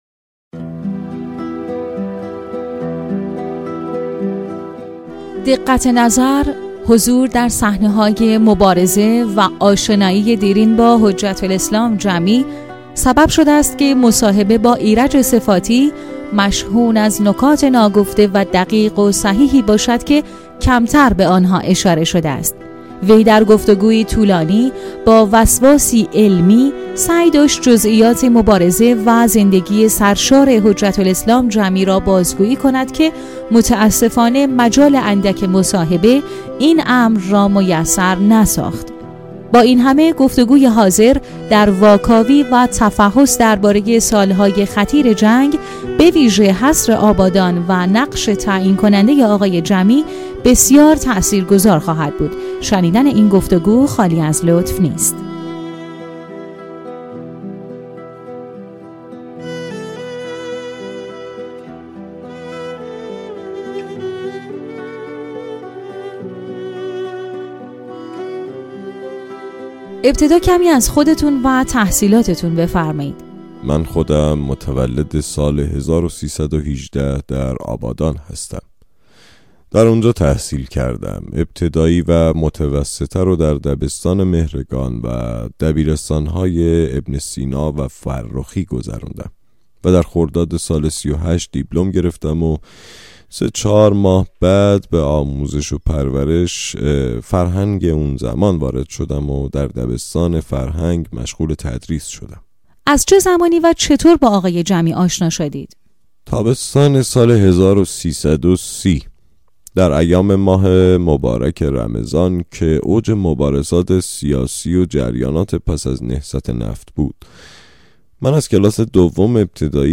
10گفت و شنود